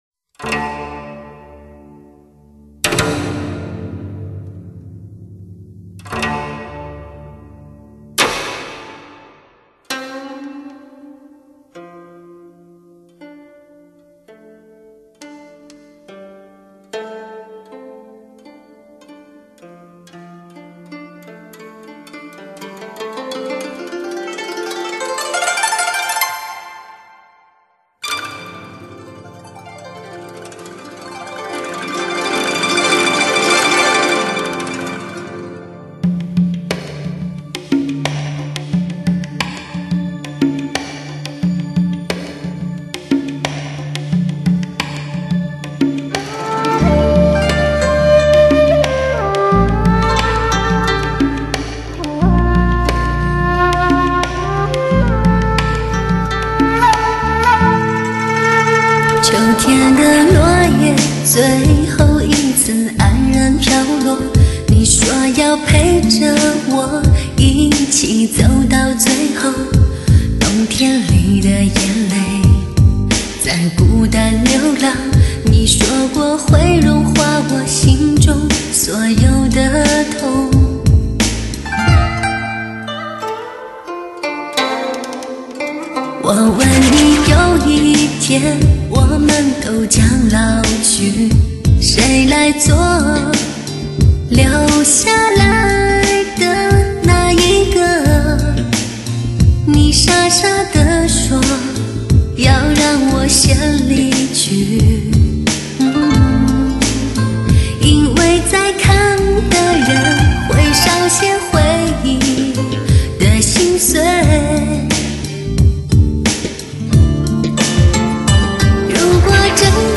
精选最醇美的声音，最纯净的情感，顶级发烧，
现代情爱的伤感，现代情歌谱写现代爱情故事……